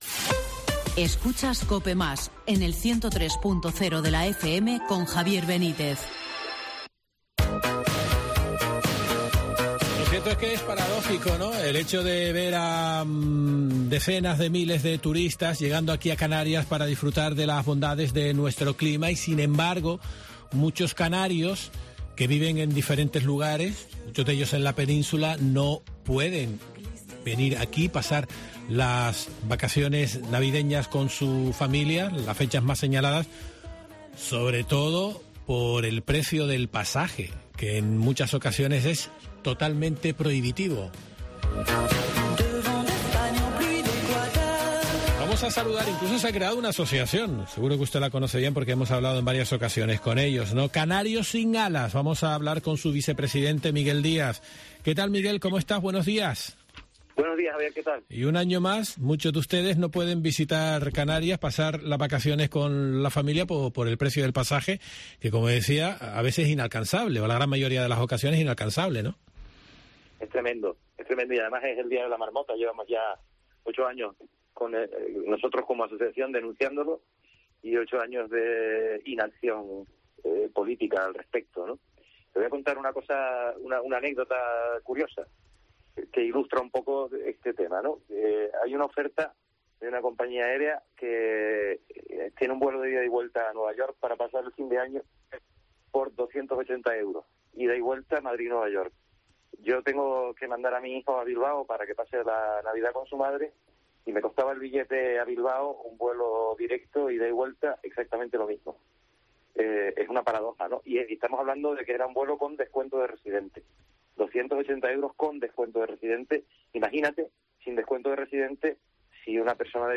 La Asociación Canarios sin Alas ha denunciado en Herrera en COPE Gran Canaria la desidia del equipo de gobierno regional anterior al desvelar que nunca ha habido ningún documento entre la consejería de Transporte y el ministerio del área para resolver el proyecto piloto que estimaba la creación de una ruta declarada de Obligación de Servicio Público entre Lanzarote y Madrid cuyo objetivo era limitar el precio del pasaje entre la península y las islas.